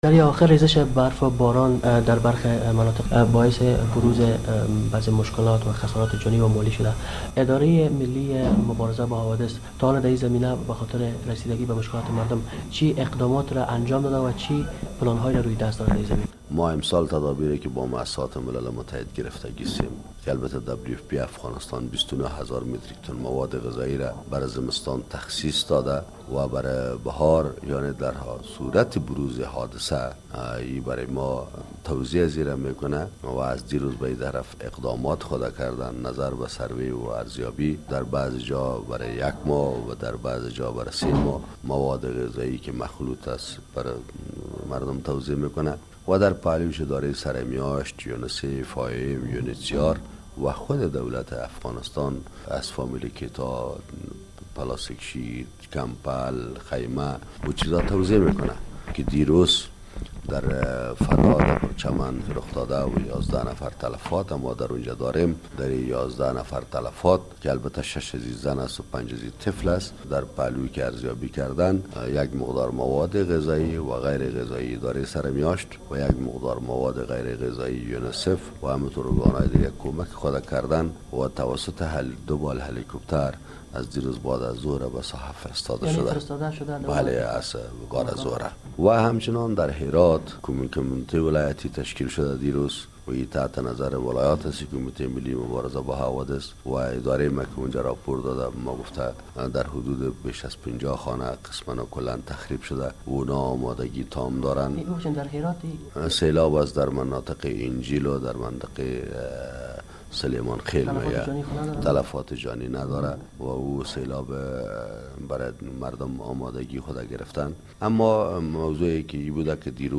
مصاحبه با عبدالمتین ادارک رییس ادارهء ملی مبارزه با حوادث طبیعی در مورد مساعدت ها به ولایت فراه